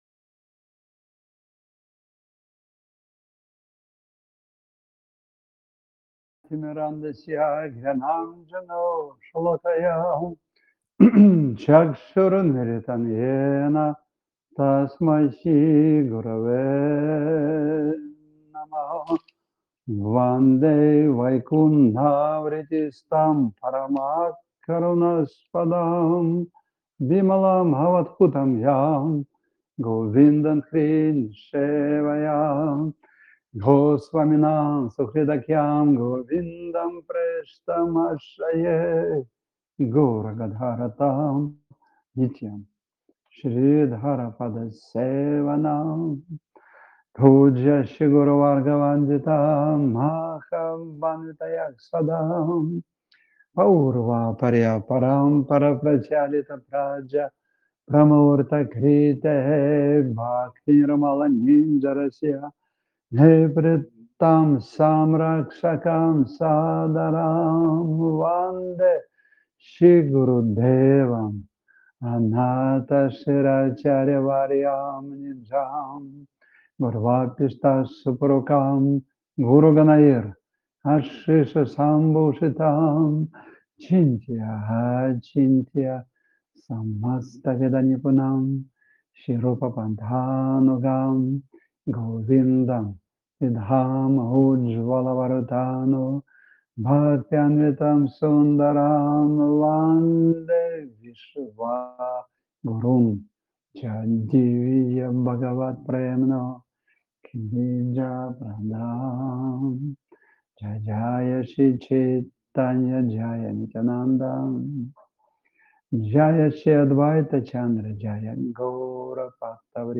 Центр Гупта Говардхан Сева Ашрам на Волге